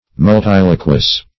Search Result for " multiloquous" : The Collaborative International Dictionary of English v.0.48: Multiloquent \Mul*til"o*quent\, Multiloquous \Mul*til"o*quous\, a. [L. multiloquus; multus much, many + loqui to speak.] Speaking much; very talkative; loquacious.